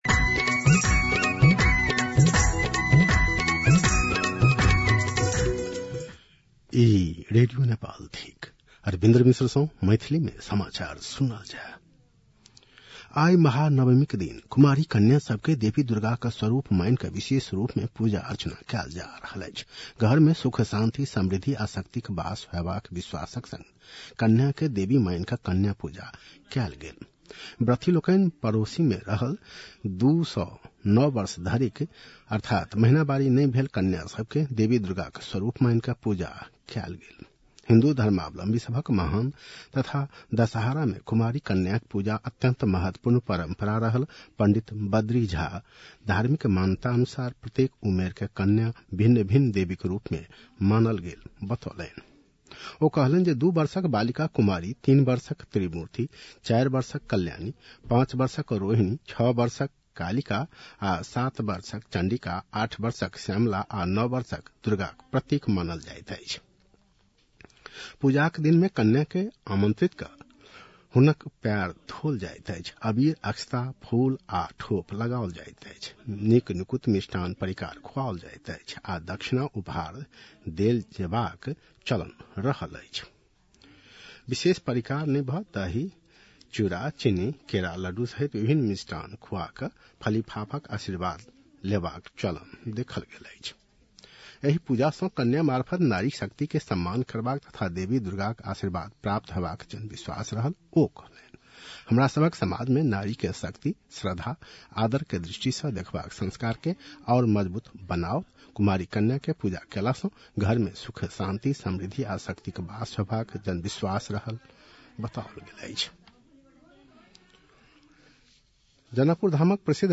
मैथिली भाषामा समाचार : १५ असोज , २०८२
6-pm-maithali-news-6-15.mp3